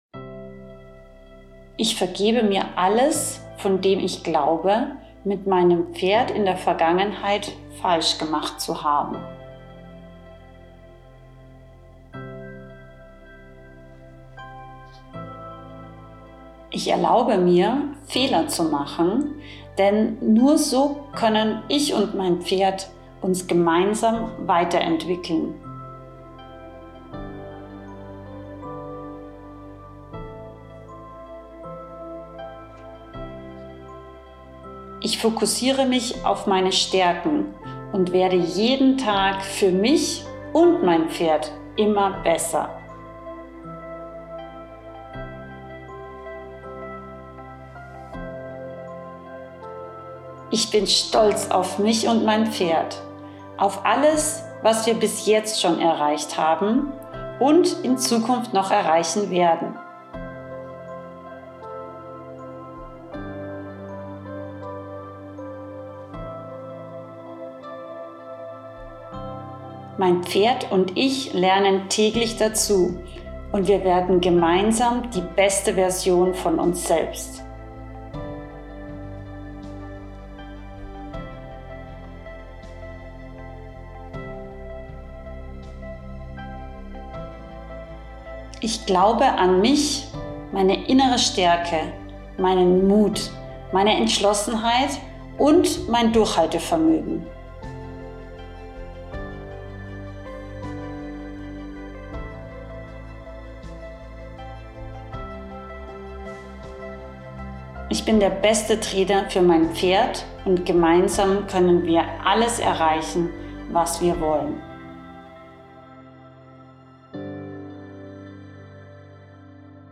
Weil in dieser Pferdepocastepisode sind NUR die AUDIO-Affirmationen zum Nachsprechen, ohne Erklärung und Intro.